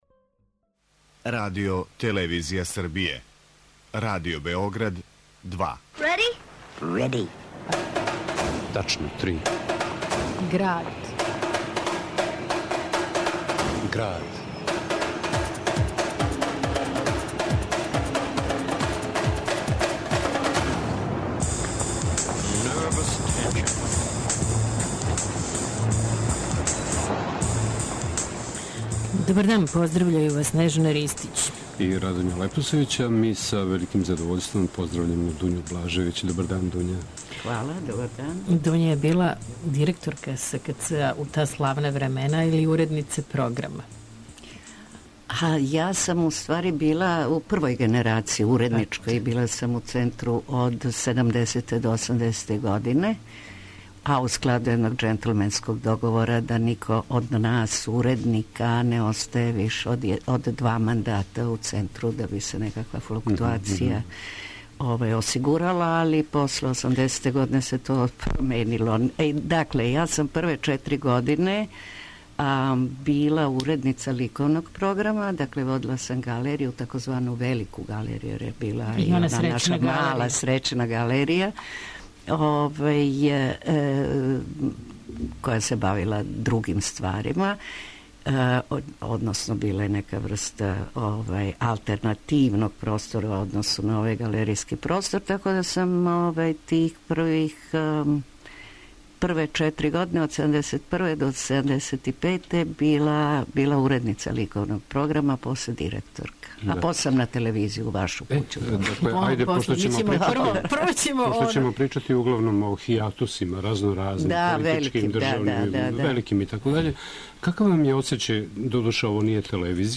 Наравно, уз бројне документарне снимке.